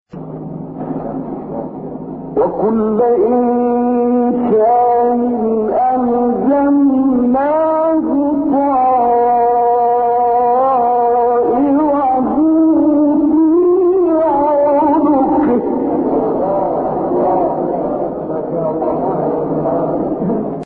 6 فراز صوتی در مقام «کُرد»
گروه شبکه اجتماعی: فرازهایی صوتی از تلاوت شش قاری برجسته مصری که در مقام کُرد اجرا شده‌اند، ارائه می‌شود.